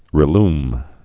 (rĭ-lm)